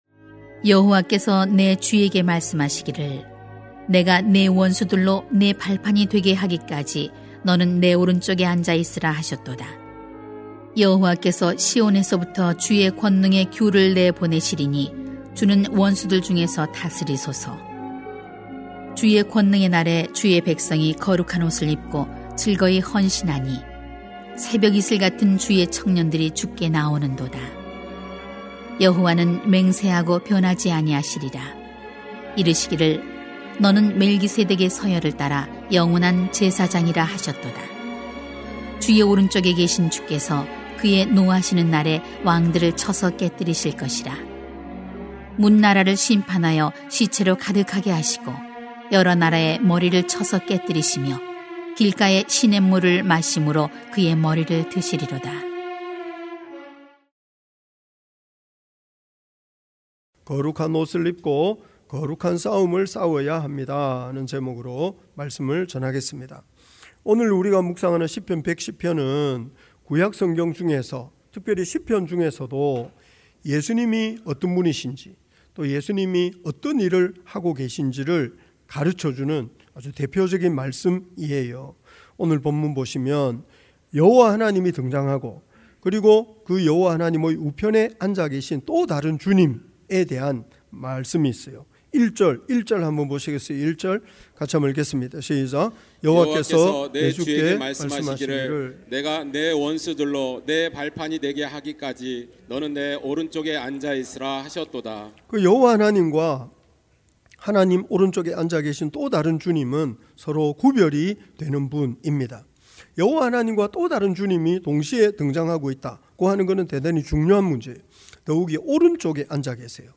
[시 110:1-7] 거룩한 옷을 입고 거룩한 싸움을 싸워야 합니다 > 주일 예배 | 전주제자교회